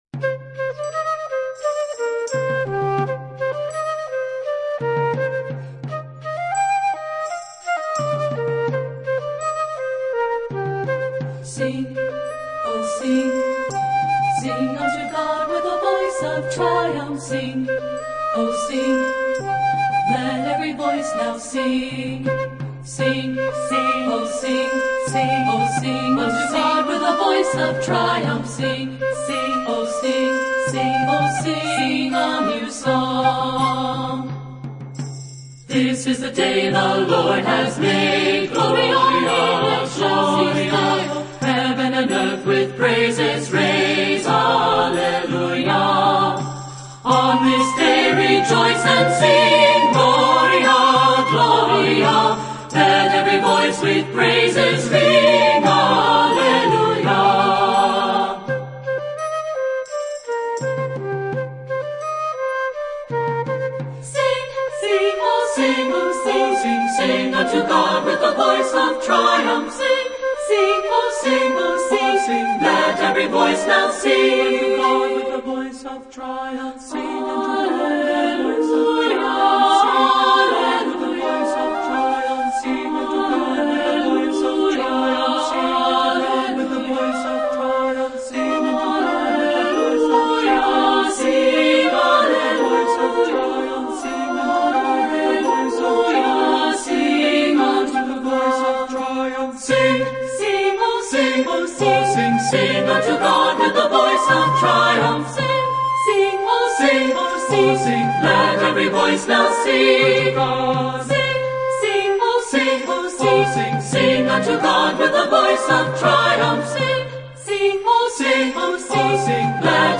Voicing: 3-Part Mixed